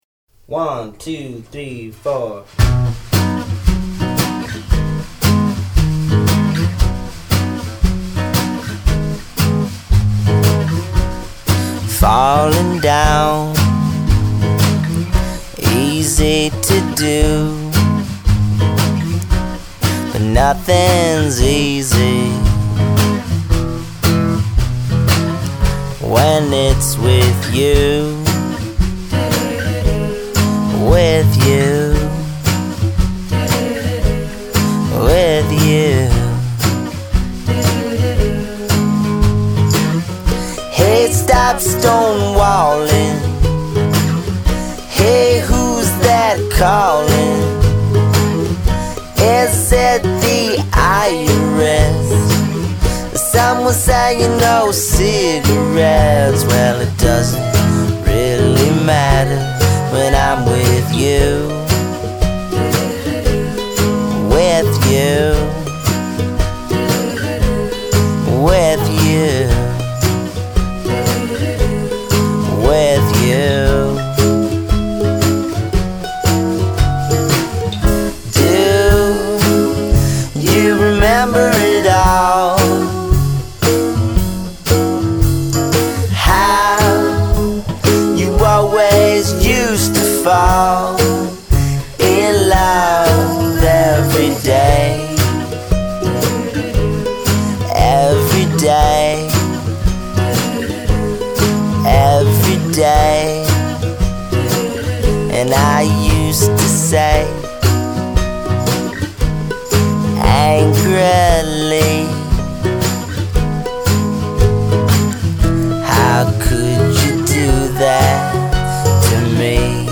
a stripped-down sound